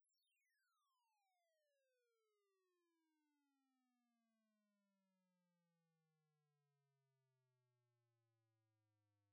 Instead, I created square waves at precise bit levels to avoid quantization errors, synchronized to the sample rate to avoid aliasing.
So, the sound starts dropping at discrete frequencies, and moves increasingly towards a smooth frequency sweep.
All files are 24-bit.
Sweep at the 16th bit level (-90.3 dB)
On a quality system at a relatively high monitoring level, you’ll have no problem hearing this sweep.
level_test_16-bit.wav